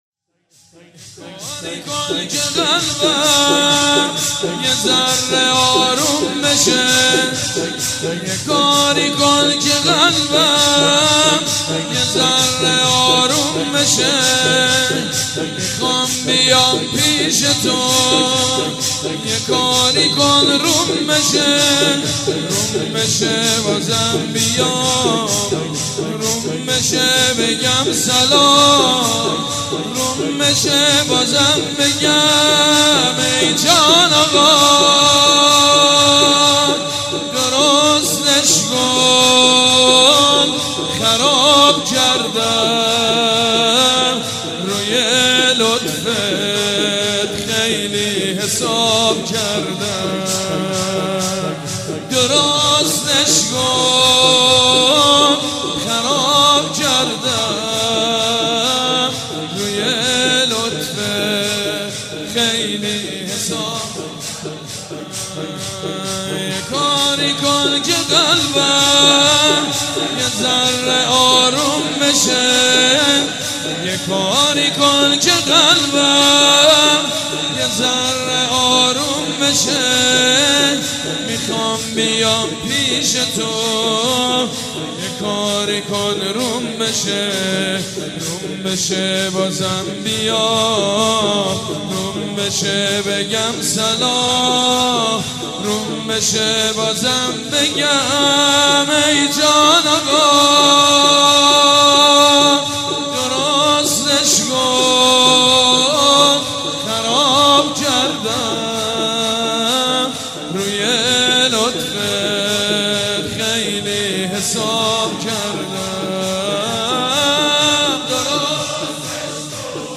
پیشنهاد عقیق/ مداحی شنیدنی حاج سیدمجید بنی فاطمه
عقیق: قطعه ای شنیدنی از مداحی حاج سید مجید بنی فاطمه در شب دوم محرم امسال.